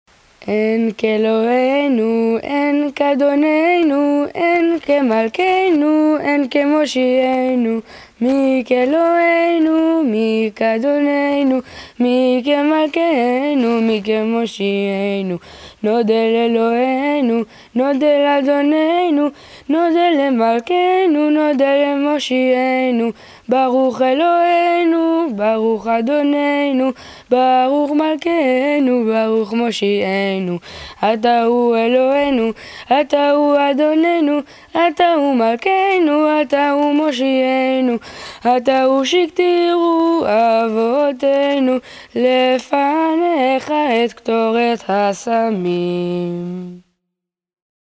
Cet hymne est chanté vers la fin de l’office du matin (ou de l’office de Moussaf du Chabbat et des fêtes).
Audio Enfants: